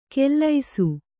♪ kellaisu